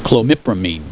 Pronunciation
(kloe MI pra meen)